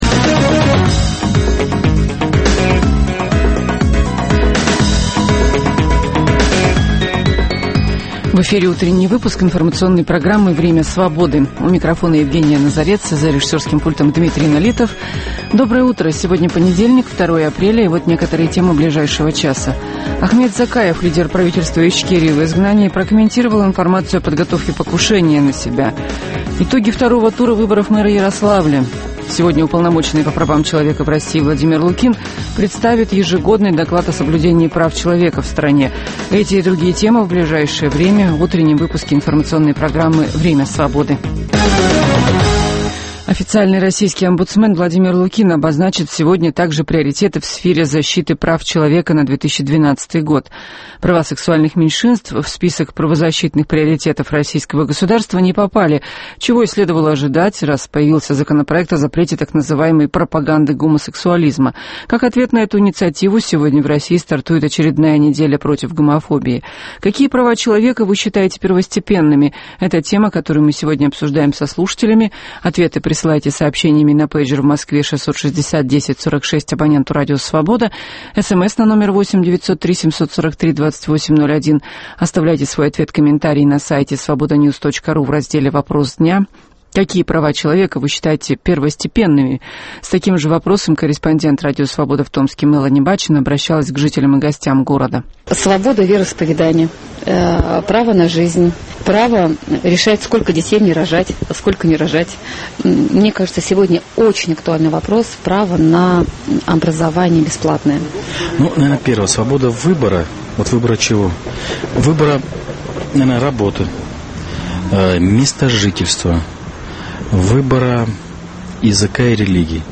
Перспективы и подробности первых событий дня наступившего, дискуссии с экспертами на актуальные темы, обсуждение вопроса дня со слушателями в прямом эфире.